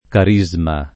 vai all'elenco alfabetico delle voci ingrandisci il carattere 100% rimpicciolisci il carattere stampa invia tramite posta elettronica codividi su Facebook carisma [ kar &@ ma ] s. m.; pl. ‑smi — estranea al sistema it. d’accentaz. una pn. sdrucciola alla greca [ k # - ]